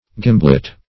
gimblet - definition of gimblet - synonyms, pronunciation, spelling from Free Dictionary Search Result for " gimblet" : The Collaborative International Dictionary of English v.0.48: Gimblet \Gim"blet\, n. & v. See Gimlet .